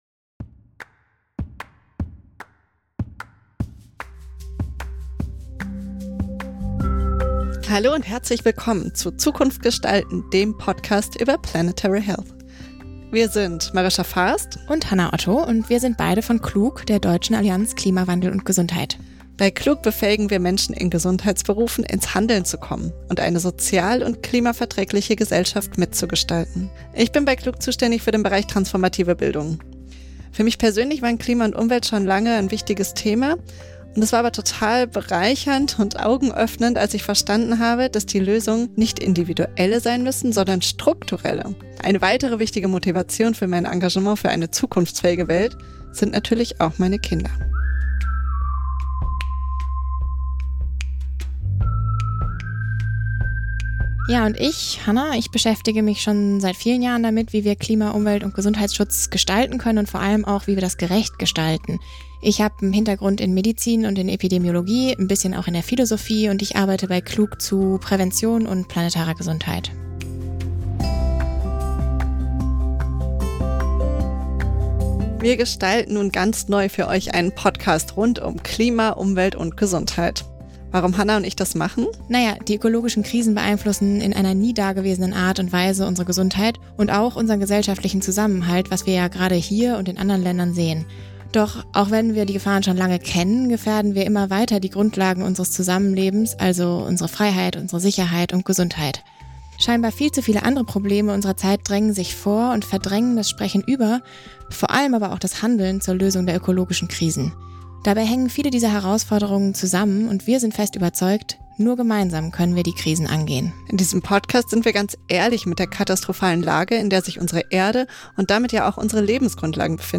Im Teaser führen wir ein in die Thematik und lassen ein paar
unserer spannenden Gäste zu Wort kommen - hör gerne mal